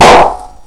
high-pitched pop
balloon balloon-pop bang high-pitched noise pop sound effect free sound royalty free Sound Effects